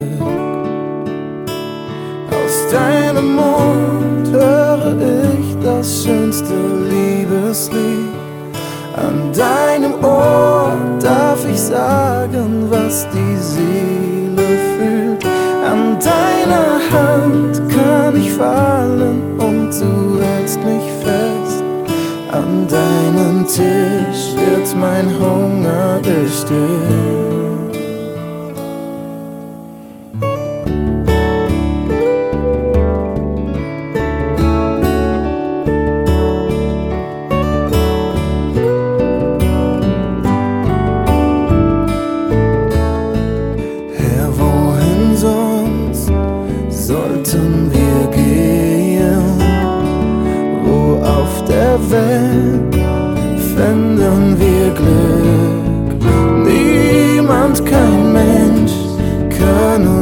Worship 0,99 €